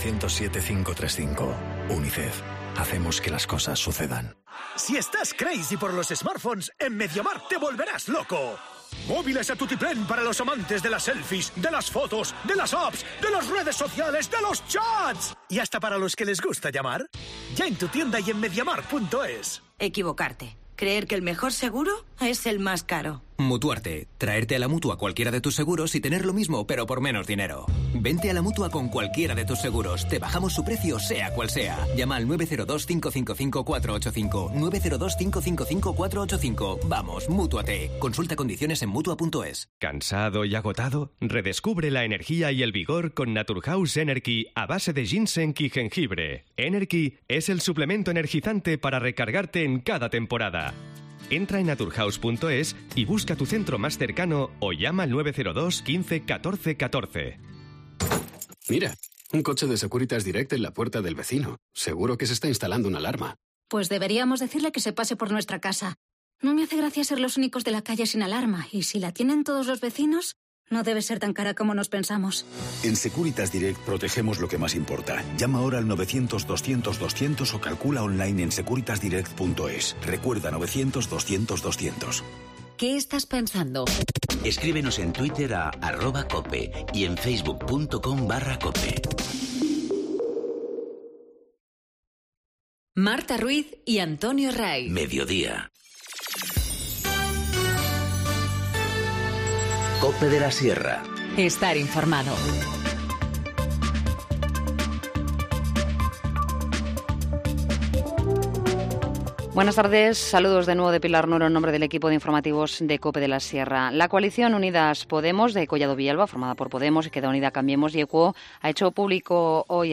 Informativo Mediodía 14 mayo 14:50h